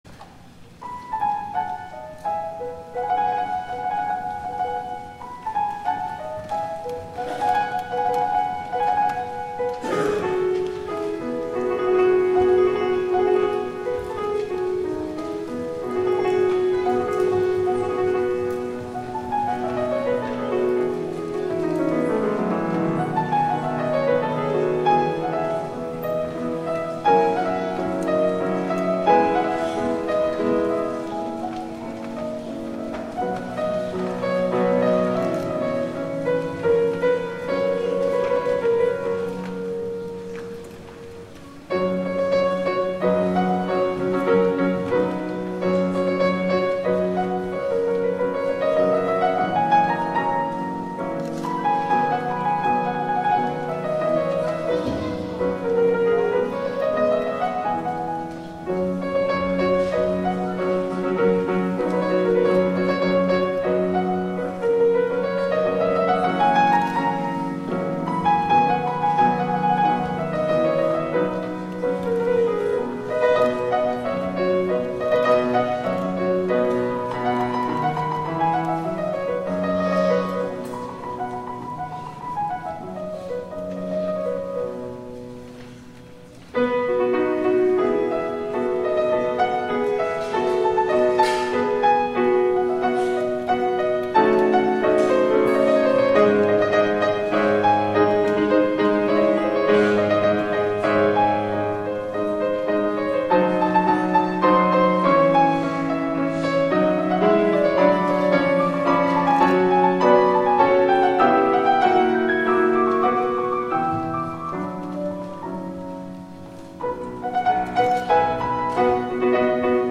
THE OFFERTORY
SonataInDMajor.mp3